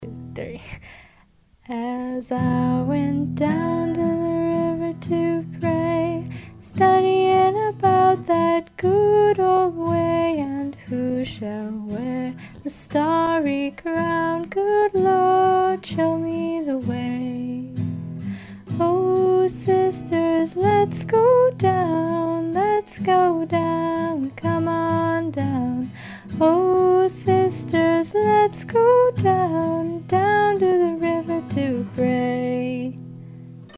Very quick sketch my girlfriend and I did tonight. We just bought a Tascam 244 and were testing it out. I am thrilled with this machine's quality so far.